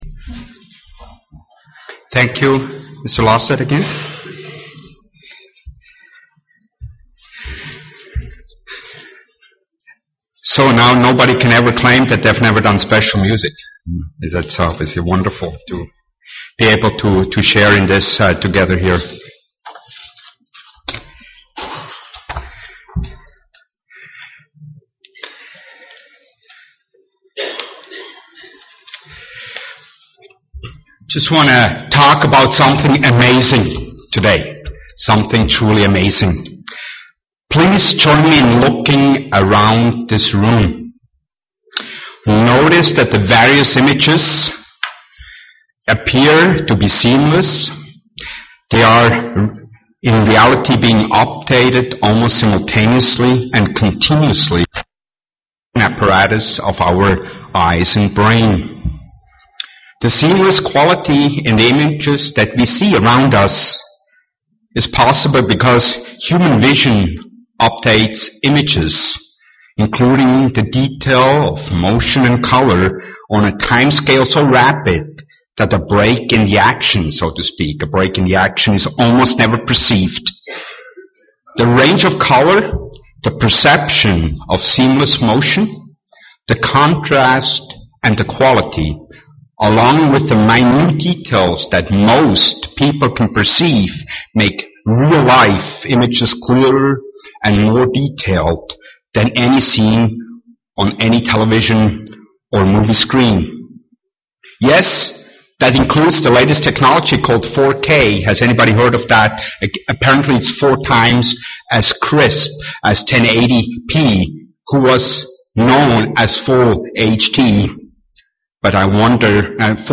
Sermons
Given in Twin Cities, MN Eau Claire, WI La Crosse, WI